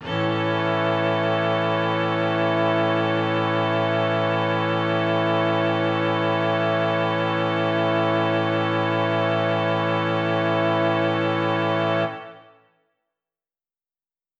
SO_KTron-Cello-Amaj.wav